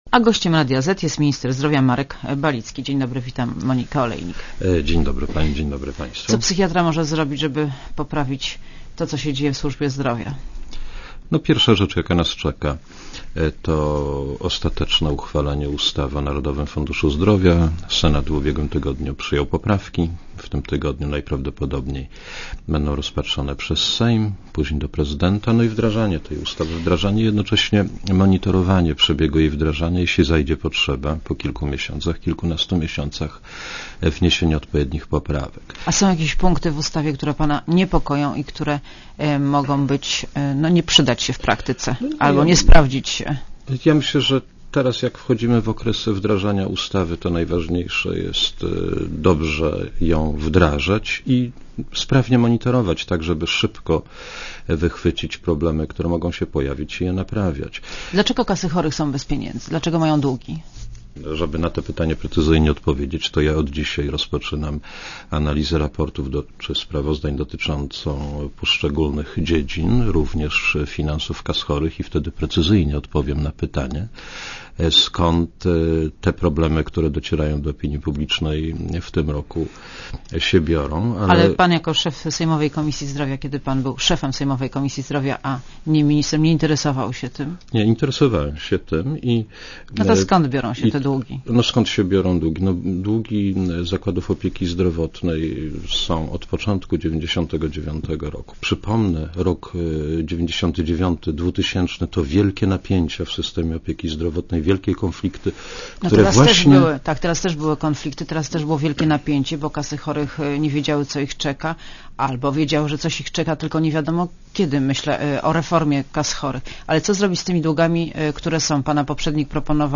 Monika Olejnik rozmawia Markiem Balickim - ministrem zdrowia